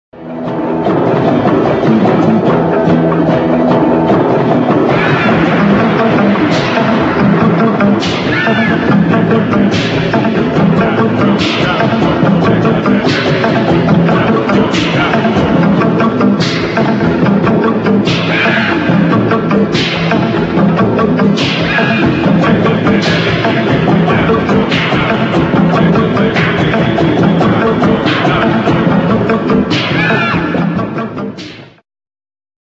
1980 thrilling medium instr.